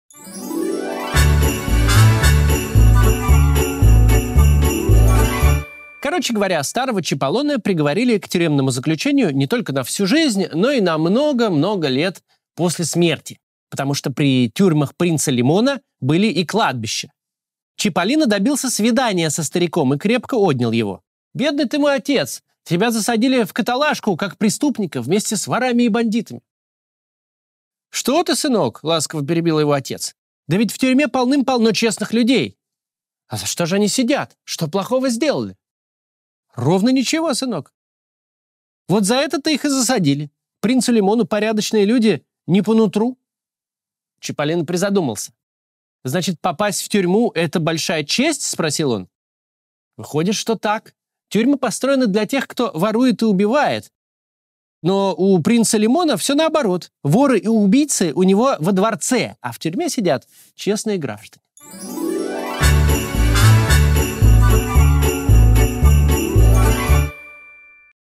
Читает Максим Кац
Максим Кац читает «Приключения Чипполино» Джанни Родари